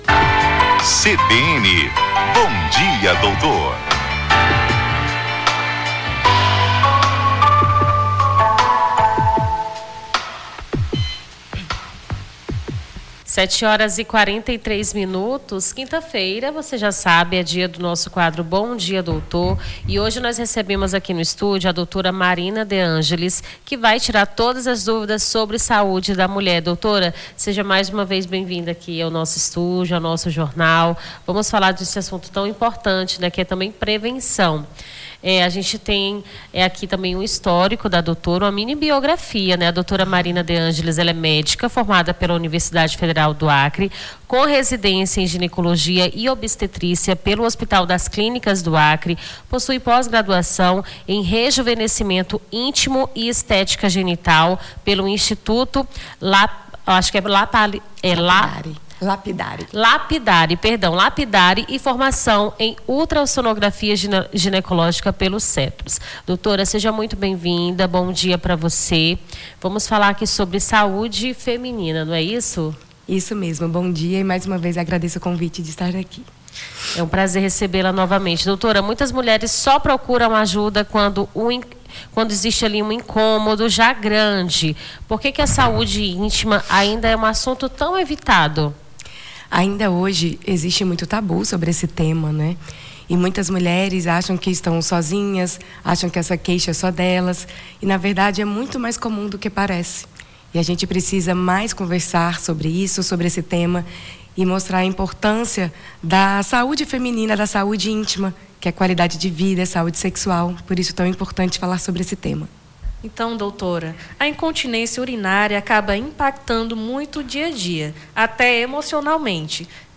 Bom dia, doutor: médica esclarece dúvidas sobre saúde da mulher